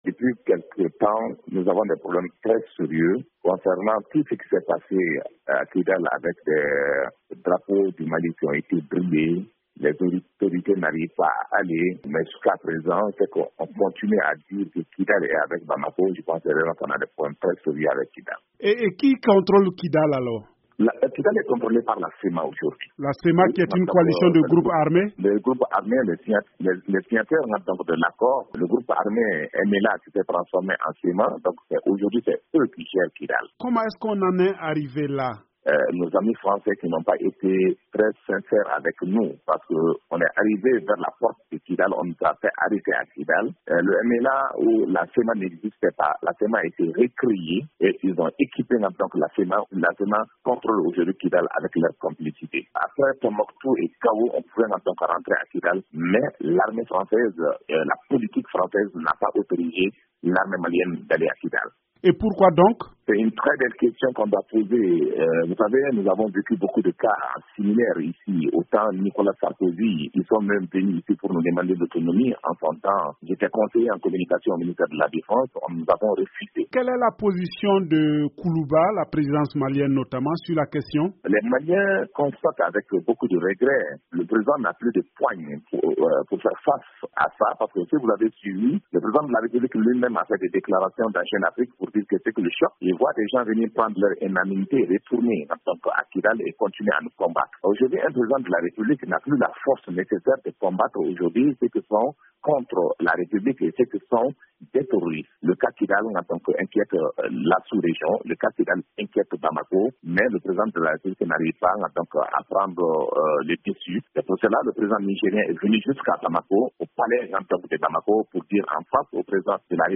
Au Mali, l’annulation mardi de la réunion du comité de suivi de l’application de l’accord de paix semble compliquer la tâche de la médiation internationale. LA CMA, la coalition ex-rebelles pose désormais des conditions pour toute participation à la tenue d’une réunion avec le gouvernement. Ce blocage fait suite aux questions soulevées autour du statut de Kidal sous contrôle des ex-rebelles. L'analyse